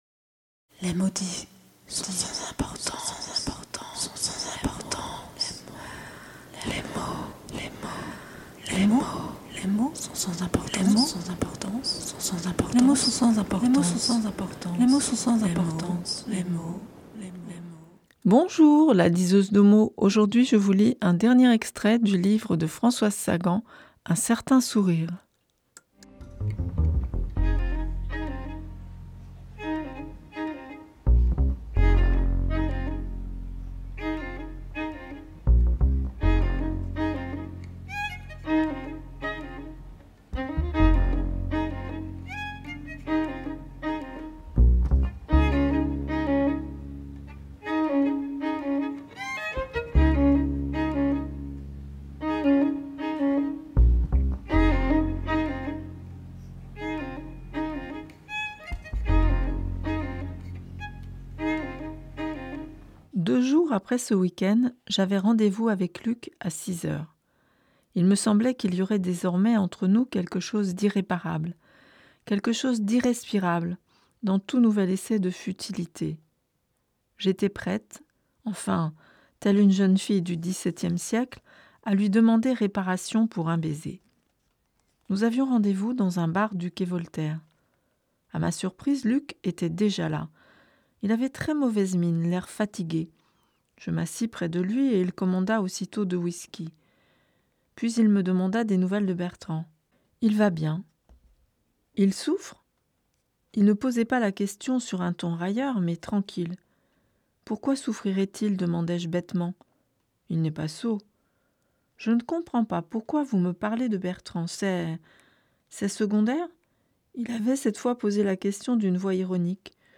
3e extrait du libre "Un Certain Sourire" de Françoise Sagan